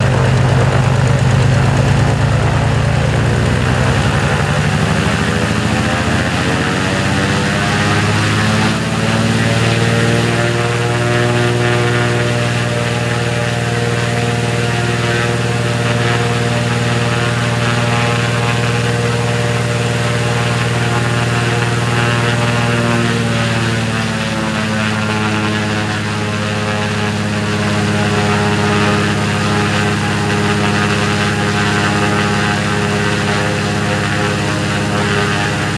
Cessna Ext Rev Up, Hold, Rev Down Mono